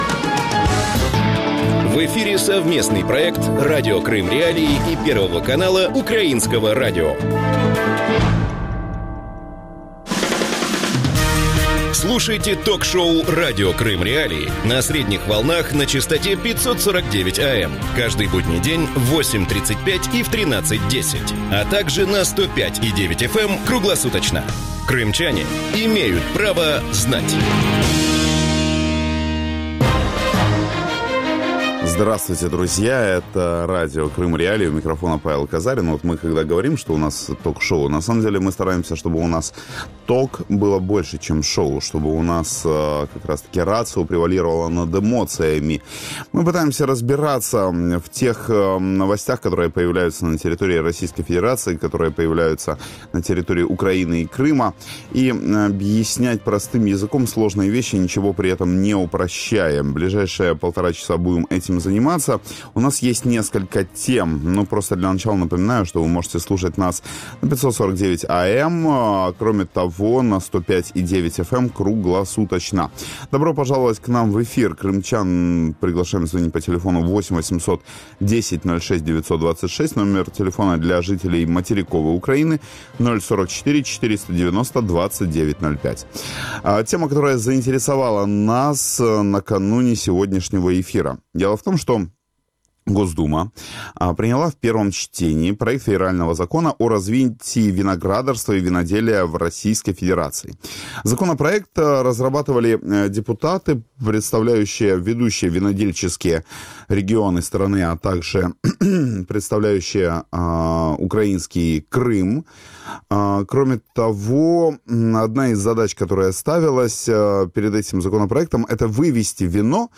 Какие перспективы у крымских виноделов? Гости эфира: Иван Плачков винодел